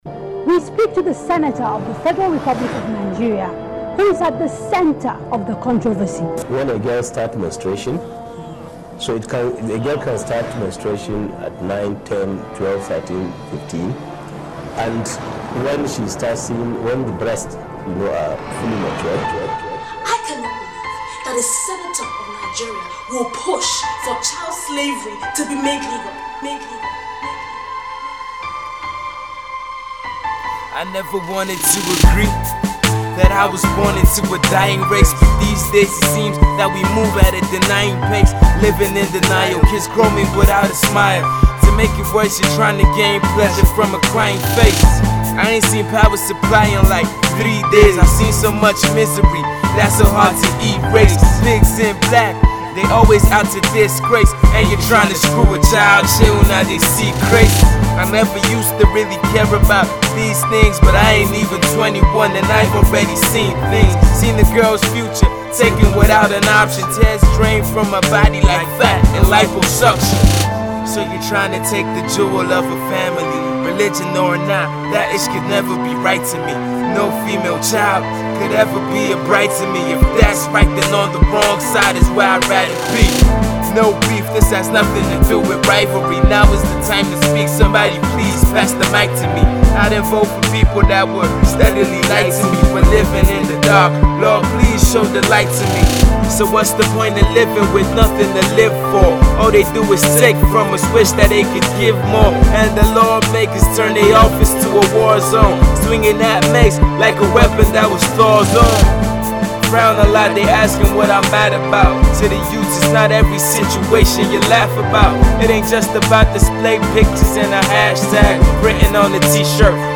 socially conscious song